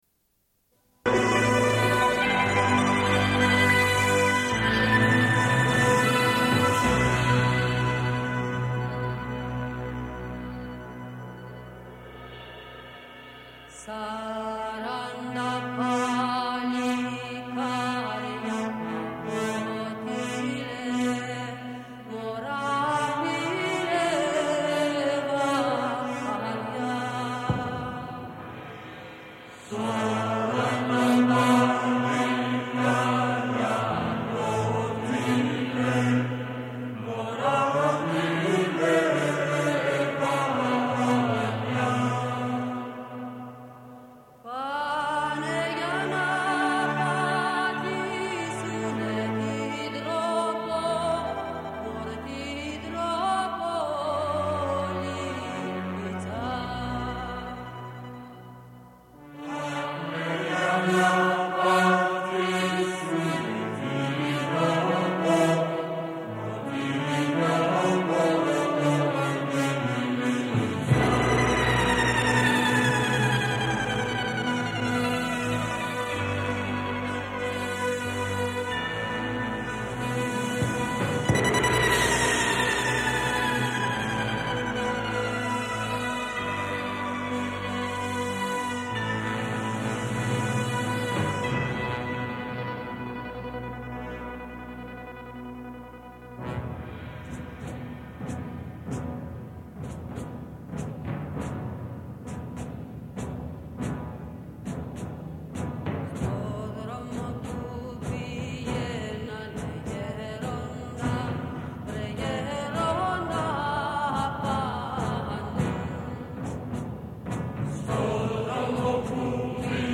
Suite de l'émission : au sujet du Festival de la Bâtie, édition 1992. Rencontre avec les femmes du comité du Festival. Rencontre avec deux comédien·nes de la troupe Mapap Teatro, au sujet de leur spectacle De Mortibus.